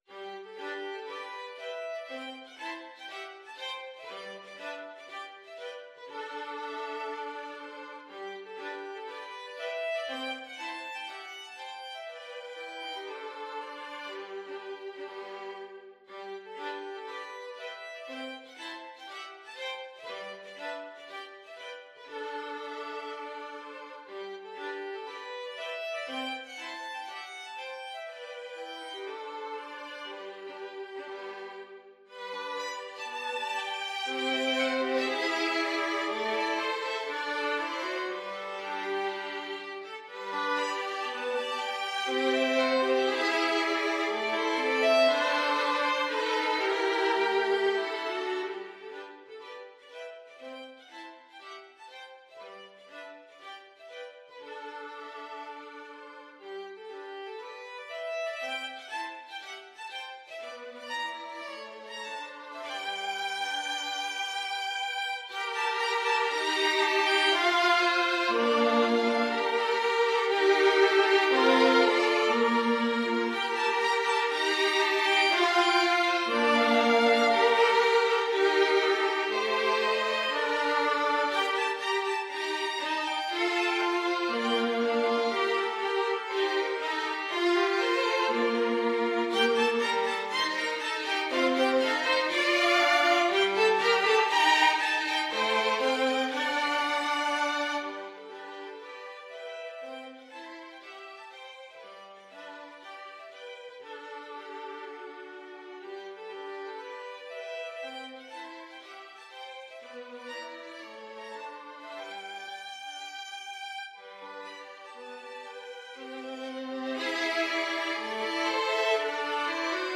Classical Dvořák, Antonín Humoresque Op. 101, No. 7 Violin Trio version
G major (Sounding Pitch) (View more G major Music for Violin Trio )
= 60 Poco lento e grazioso